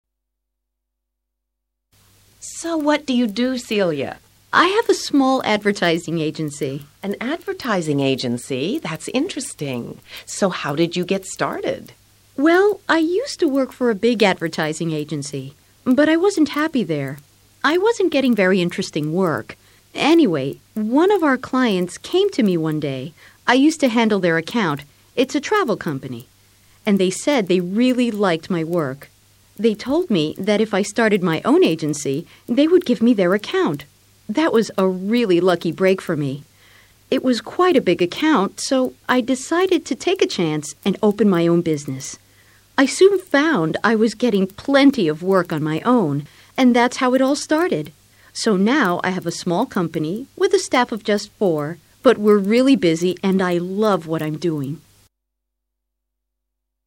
Tres personas exponen cómo lograron alcanzar sus objetivos profesionales.